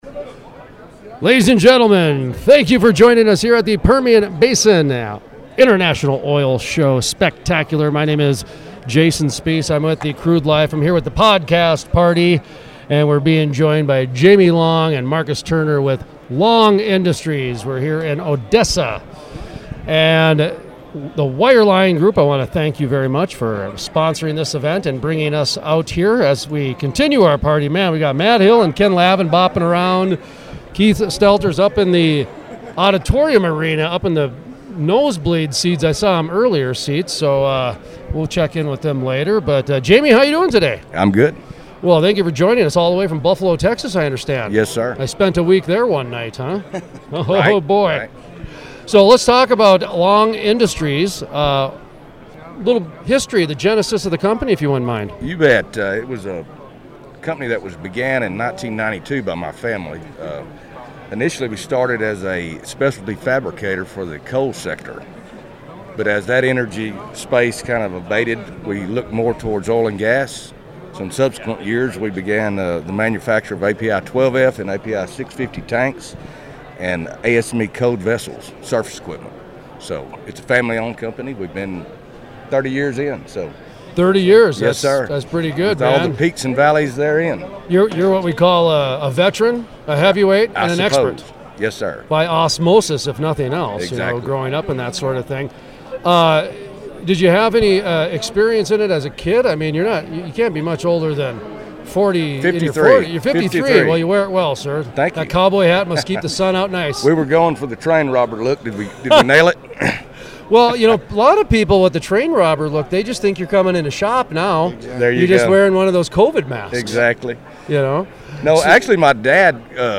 Full Length Interviews